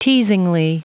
Prononciation du mot teasingly en anglais (fichier audio)
teasingly.wav